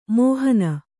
♪ mōhana